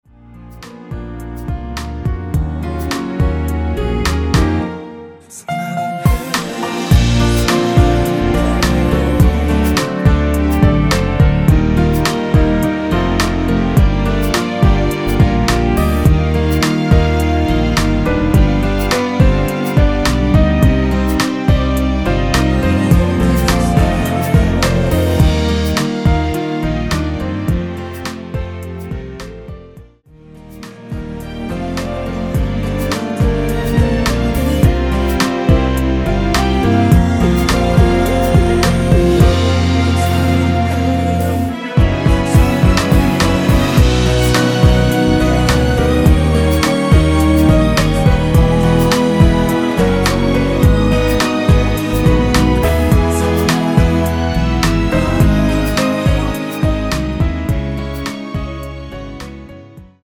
원키에서(-2)내린 코러스 포함된 MR 입니다.(미리듣기 확인)
Db
앞부분30초, 뒷부분30초씩 편집해서 올려 드리고 있습니다.
중간에 음이 끈어지고 다시 나오는 이유는